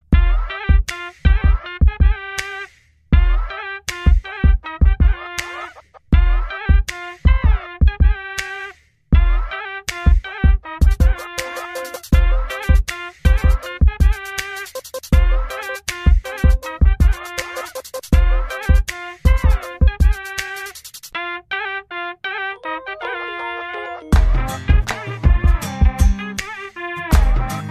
tamil bgm